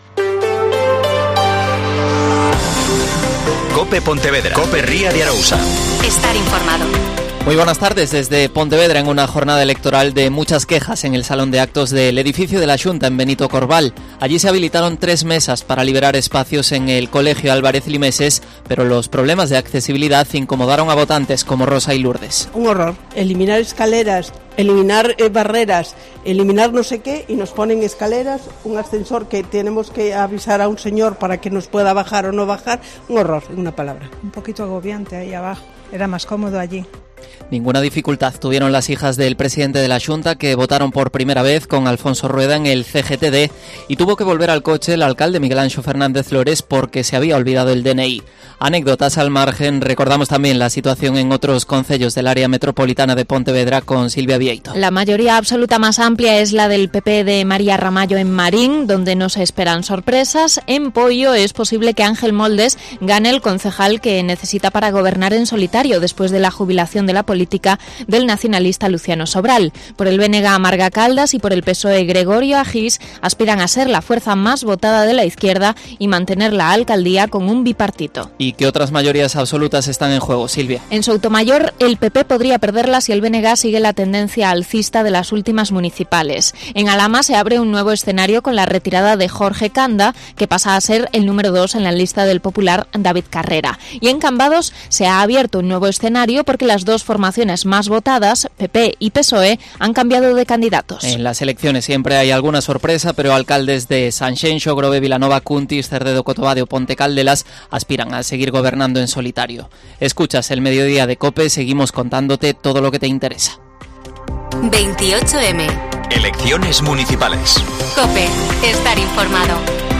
Especial Elecciones Municipales 2023 (Informativo 14,05h)